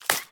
Sfx_creature_babypenguin_hop_06.ogg